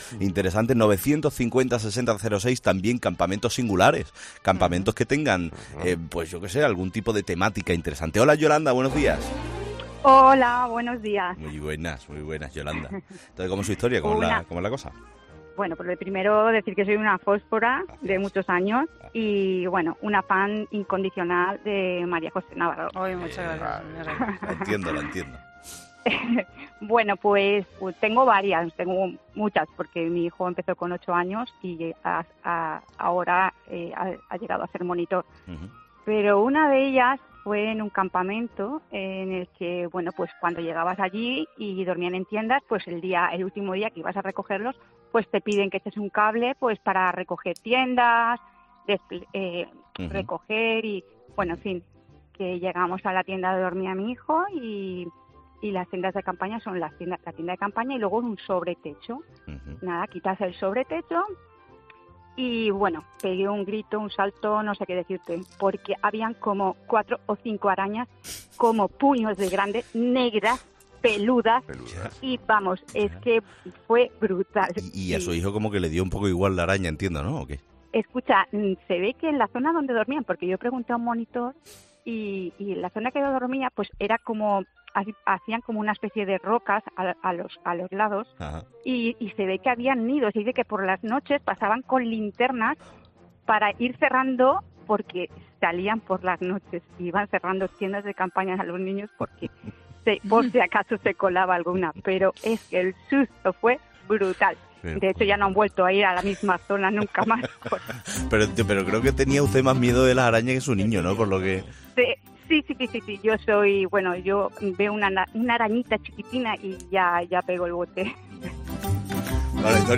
Nuestros 'fósforos', en esta ocasión, nos cuentan lo que han vivido en los campamentos de verano (o bien sus familiares más cercanos)